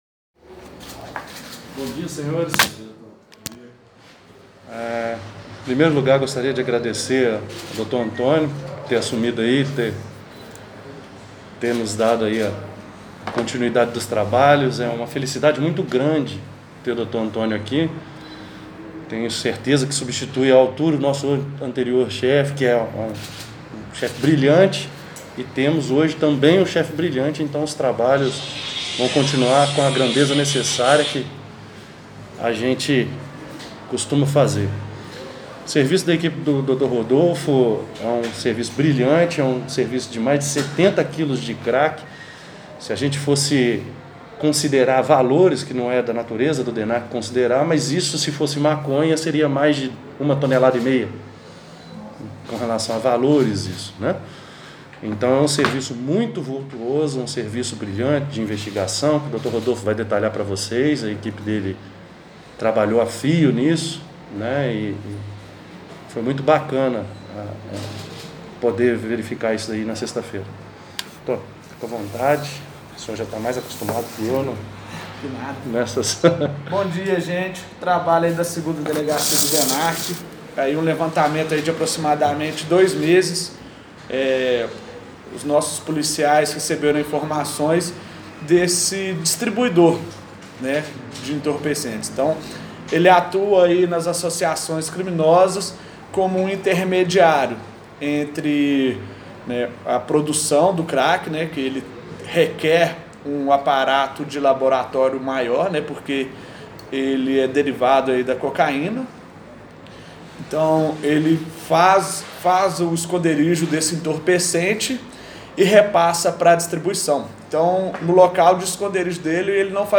Ouça a sonora com a coletiva de imprensa